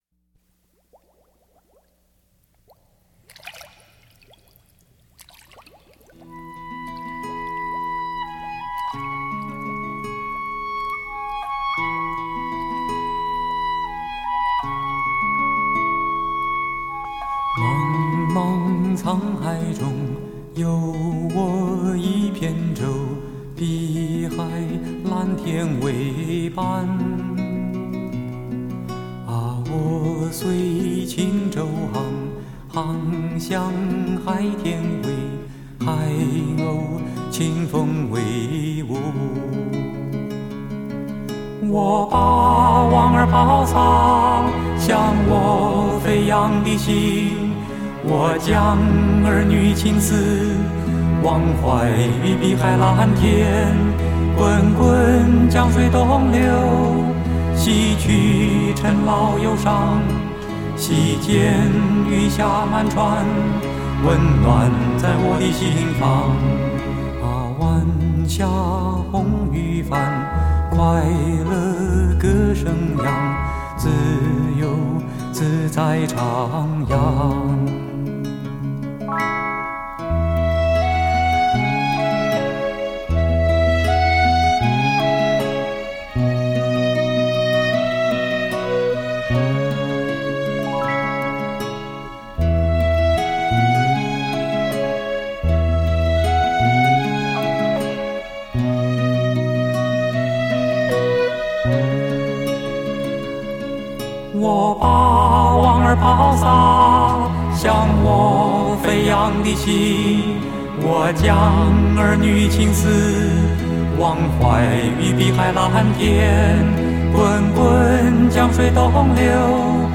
嗓音浑厚且具刚毅之气特别适合擅长诠释民谣类型歌曲唱作俱佳持续活跃台湾歌坛
编曲巧妙地在歌曲开始前加入水声的音效，更加添了空间的想像感。